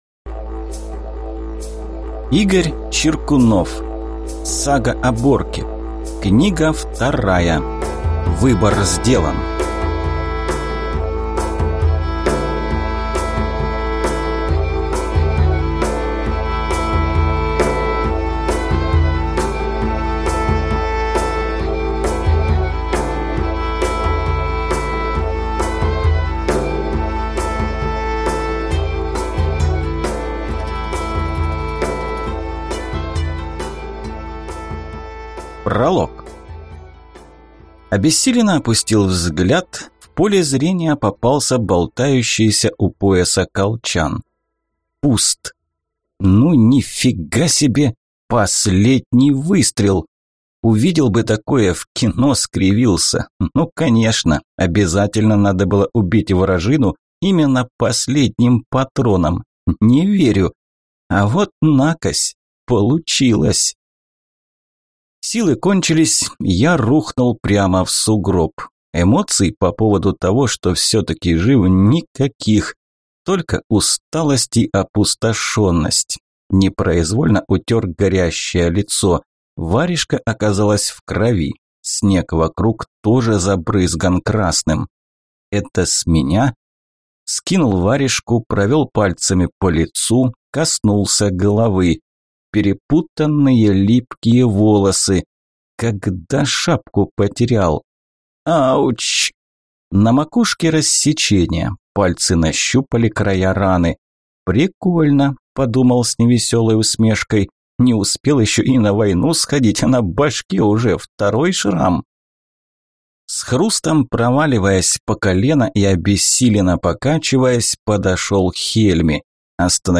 Аудиокнига Выбор сделан | Библиотека аудиокниг